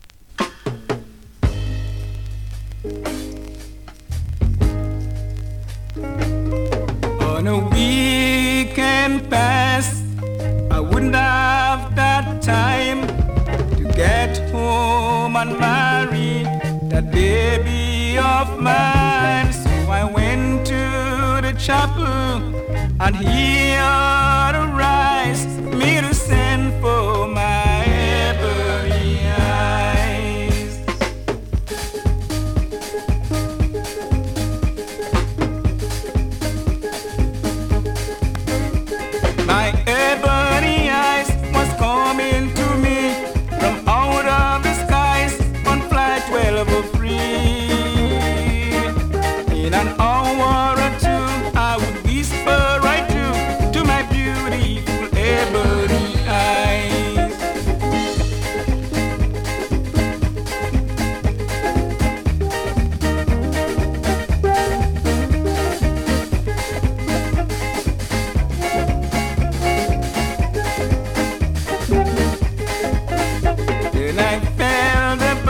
スリキズ、ノイズかなり少なめの